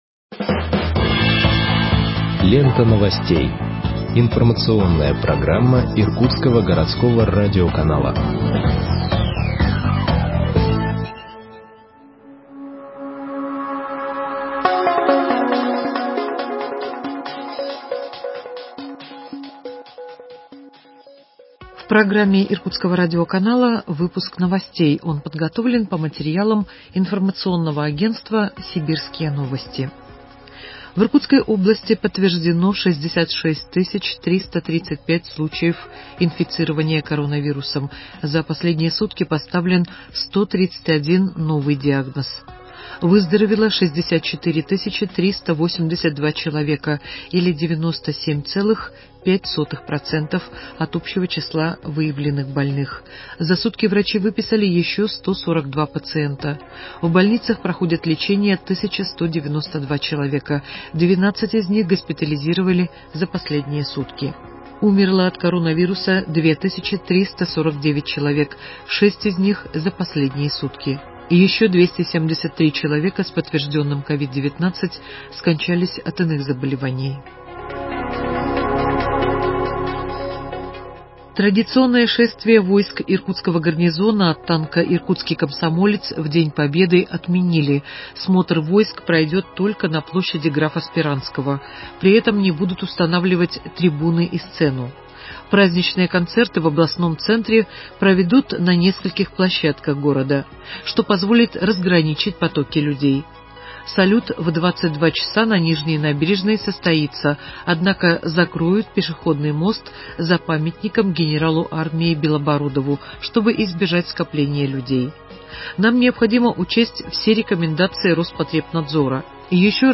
Выпуск новостей в подкастах газеты Иркутск от 30.04.2021 № 2